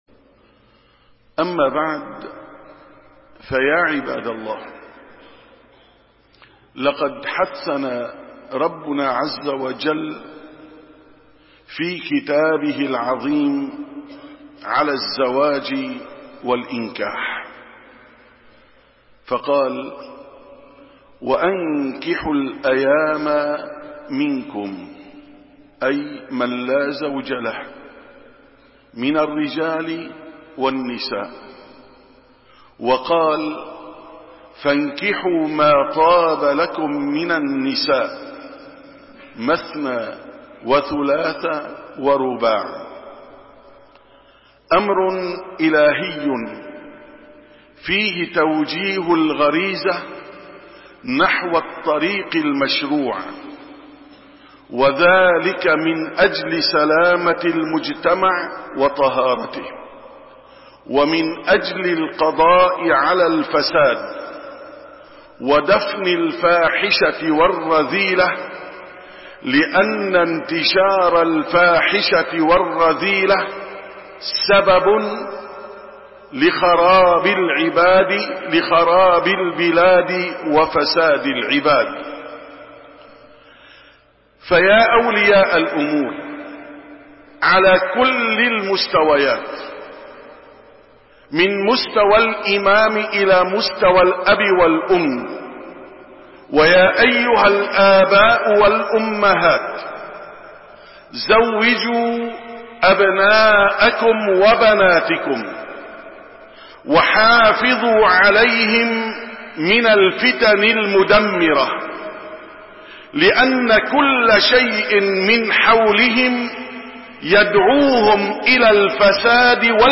646ـ خطبة الجمعة: ابحث عن زوج لابنتك